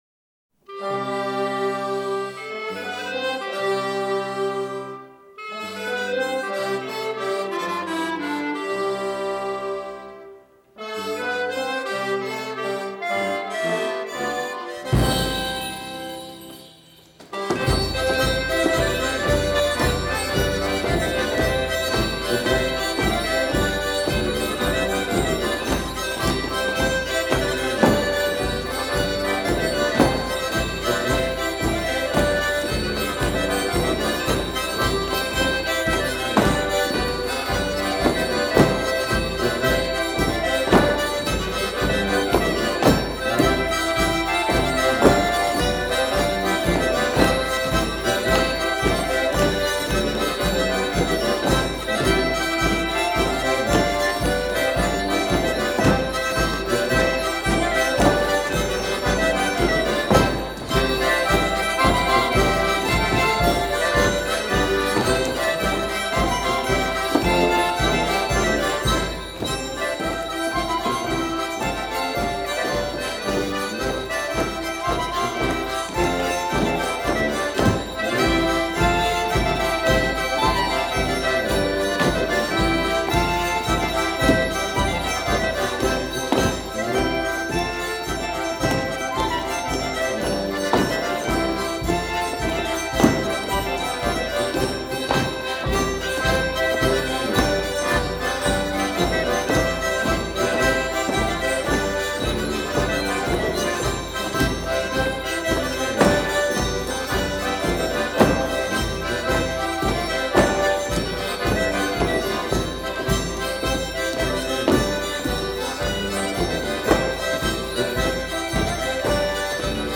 Trad.